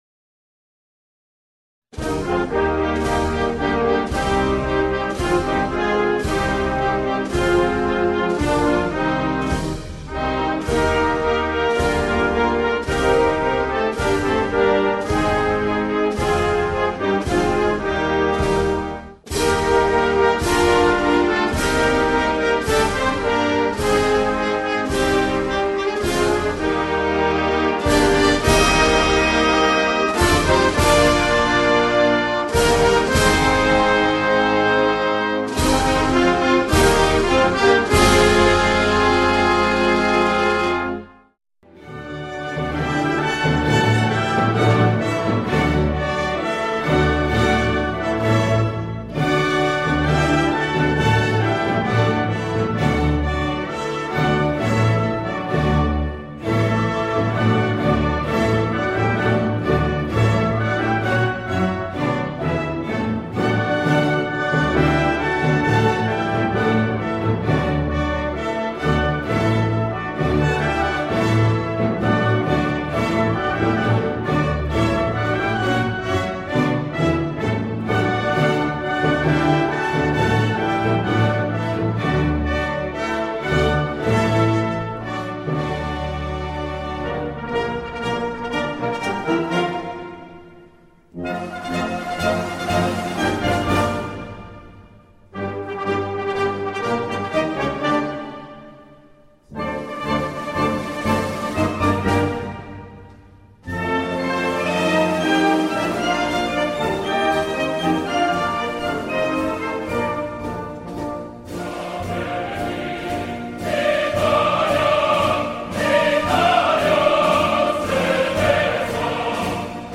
Inni audio 2025-2026